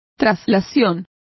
Complete with pronunciation of the translation of translation.